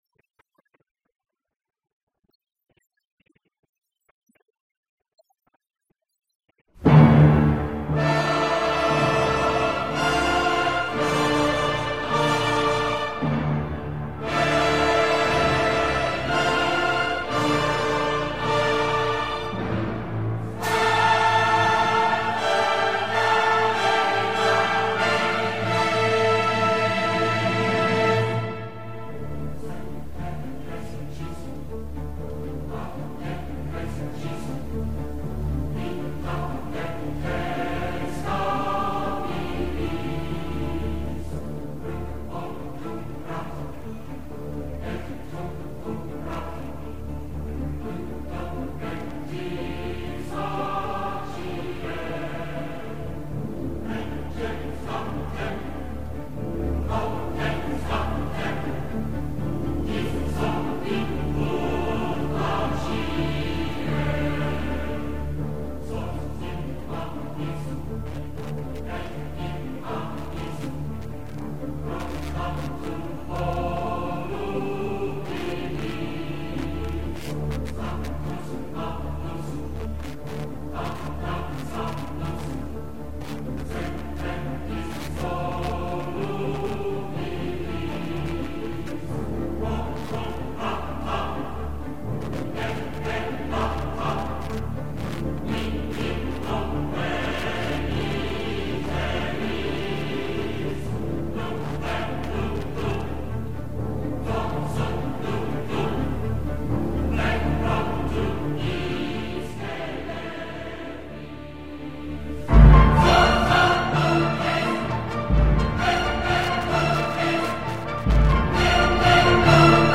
بيانات مقام معظم رهبري در باره فرد بسيجي